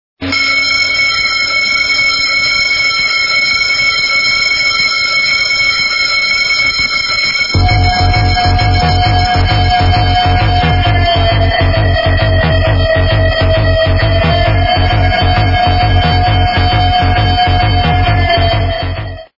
Будильники